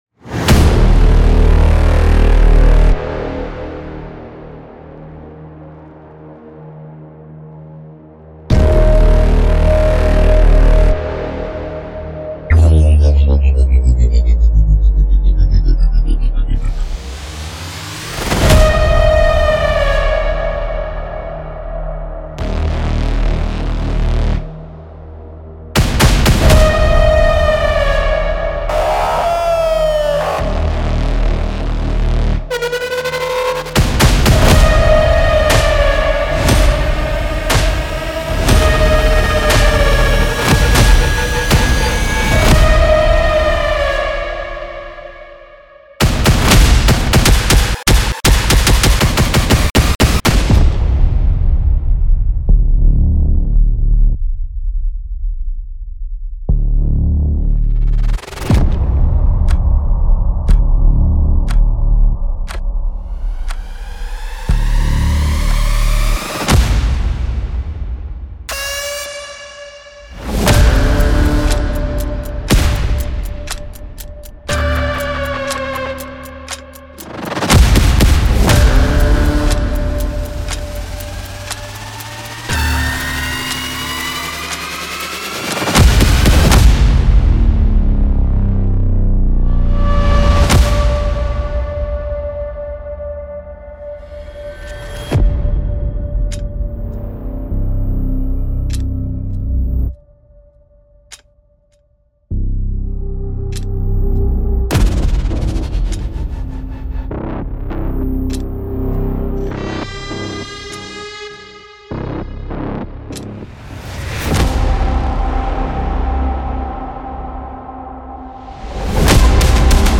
この高オクタン値のシネマティックサウンドエフェクトライブラリは、激しいアクション、サスペンス、そして軍事グレードのスリラーに最適です。
965以上の慎重にデザインされたサウンドを収め、合計4.75GBに及ぶこのパワフルなコレクションは、攻撃的なパルス、金属的な衝撃音、加速するパーカッション、そして深く威圧的な低音のブラス・スタブを提供し、絶え間ない緊張感と劇的なビルドアップを生み出します。
爆発的なサブヒットや空襲警報、打撃音の強いインダストリアルドラム、ダークなパルス音まで、Aggressorに収められたすべてのサウンドは、最大のインパクトを与えるように設計されています。
内部には、特徴的な予告編サウンド、ウーシュ音、ライザー、スタッター、カウントダウン時計、キュー・スタート音、重いインダストリアルドラムループやダビーなエレクトリックベースが脈打つ音が含まれています。
デモサウンドはコチラ↓
Genre:Action